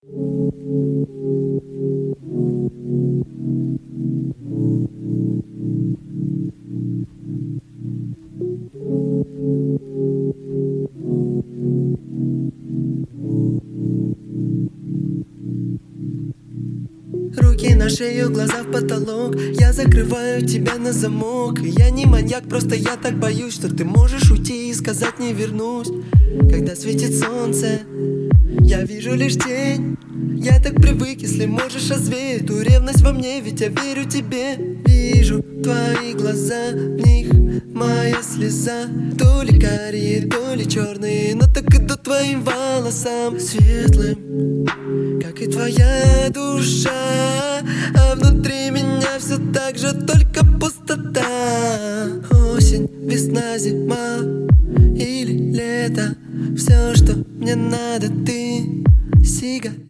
лирика
Хип-хоп
русский рэп
чувственные